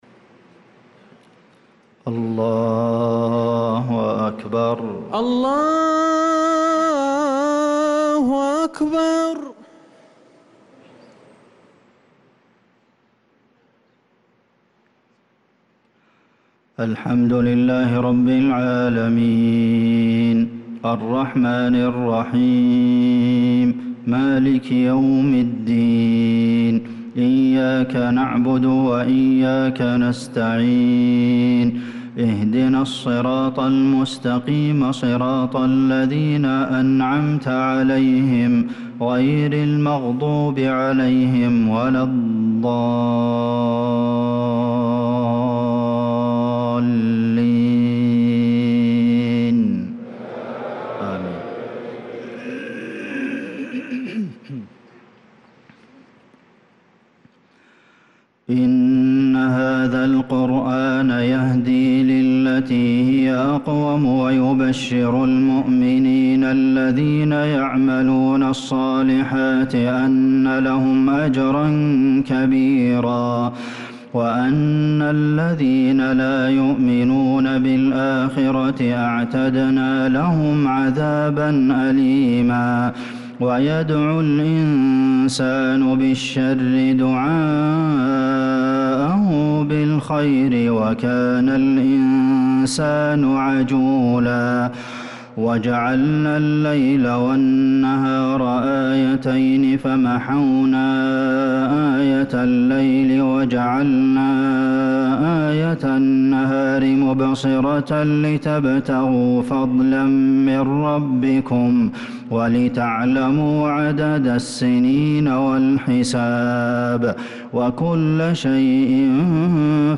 صلاة العشاء للقارئ عبدالمحسن القاسم 13 ربيع الآخر 1446 هـ
تِلَاوَات الْحَرَمَيْن .